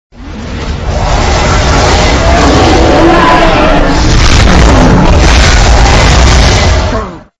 balrog_scream.mp3